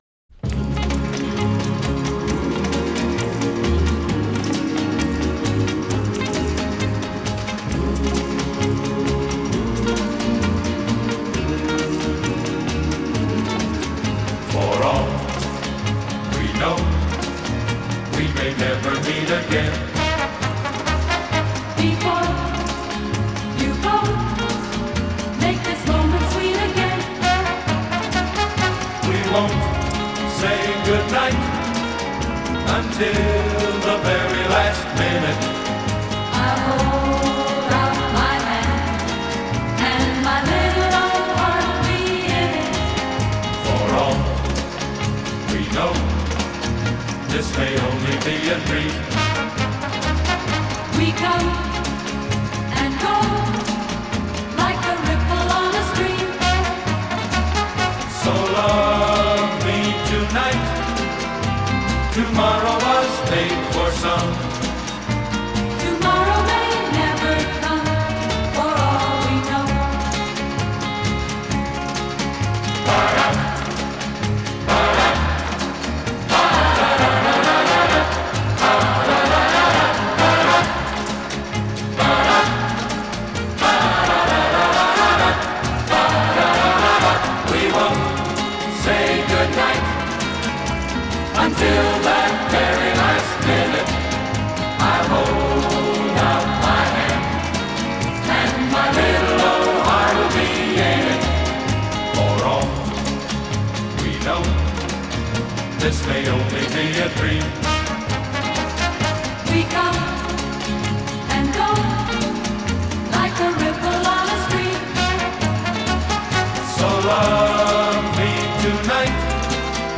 Жанр: Instrumental